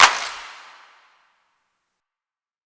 Southside Clapz (24).wav